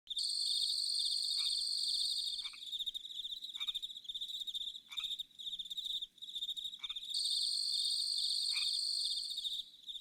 Swamp Crickets
Swamp Crickets is a free nature sound effect available for download in MP3 format.
yt_zZ0V46DxLwg_swamp_crickets.mp3